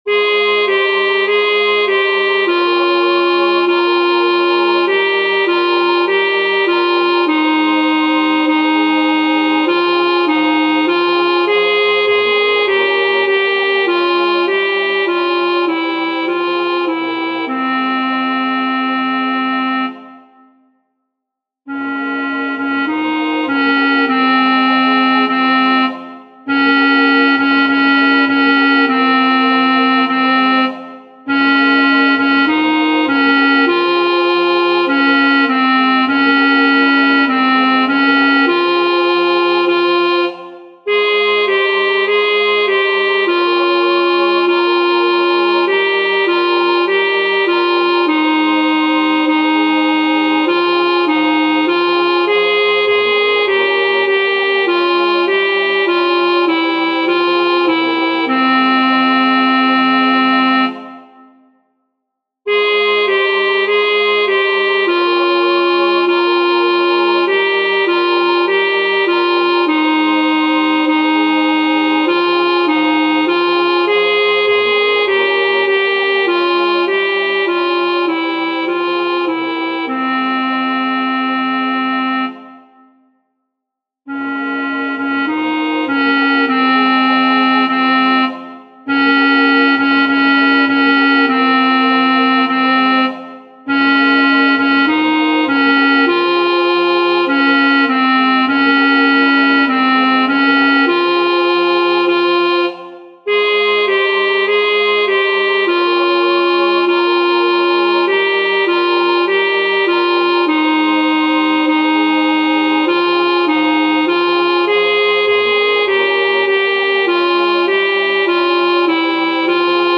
• El tempo indicado es lento.
Aquí os dejo las versiones MIDI de las cuatro voces para su estudio:
veante-mis-ojos-alto-1.mp3